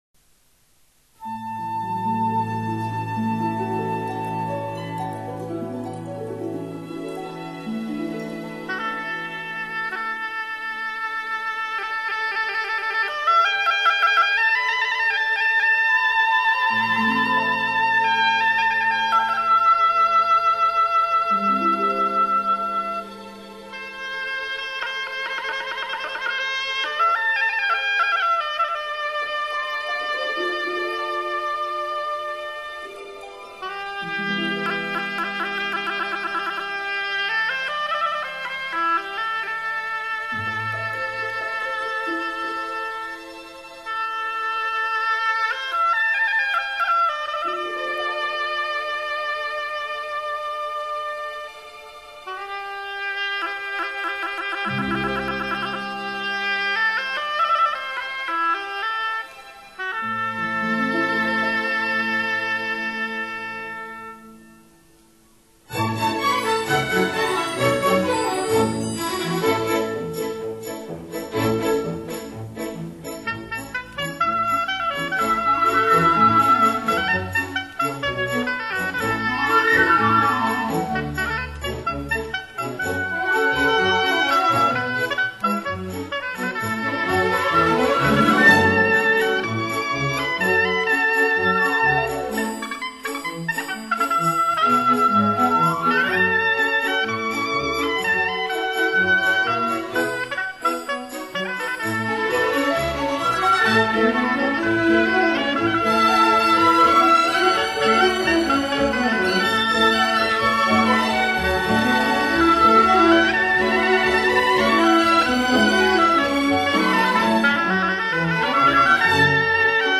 CD 3 [ 吹奏乐器（3）]
双簧管独奏